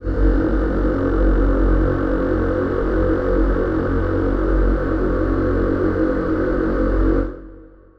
Choir Piano (Wav)
F#1.wav